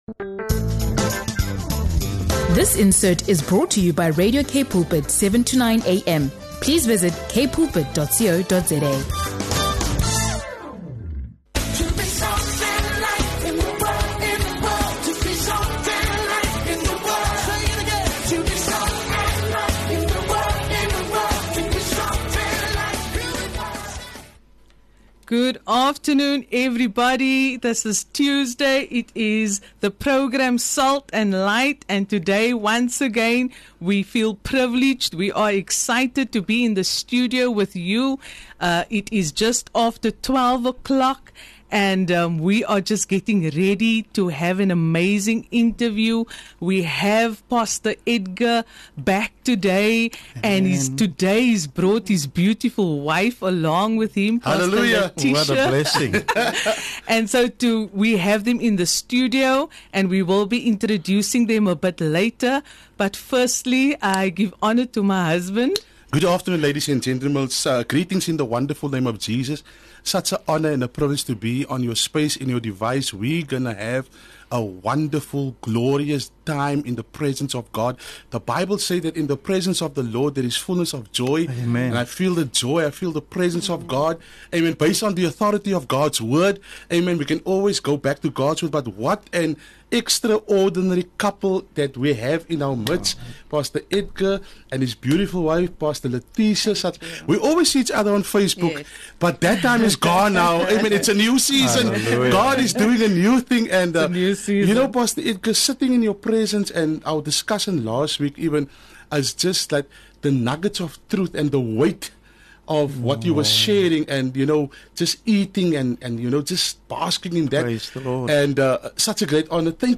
Whether you’re a ministry leader, a parent, or a believer seeking encouragement, this conversation is filled with insight, grace, and practical wisdom for navigating life and leadership with a Christ-centered foundation. Tune in for an uplifting episode that highlights faithfulness, spiritual parenting, and the beauty of walking closely with God.